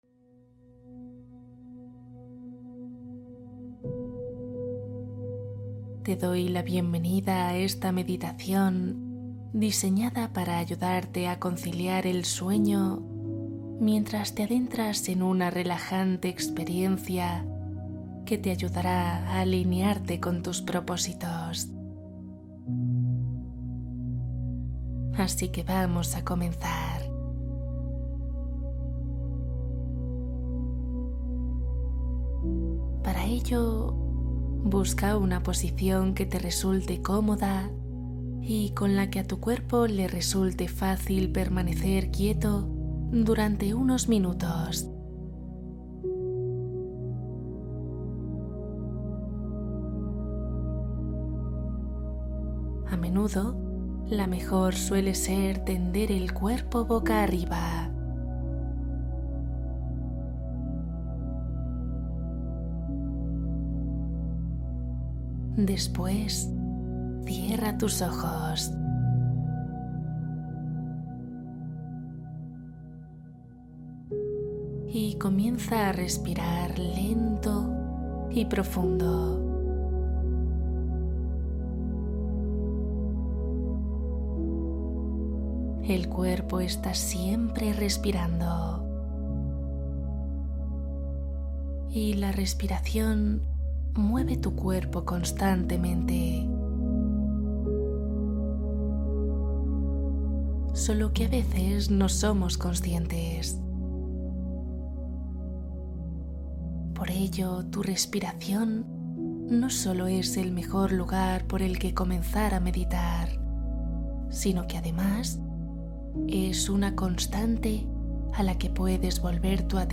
Renacer nocturno: meditación para soltar preocupaciones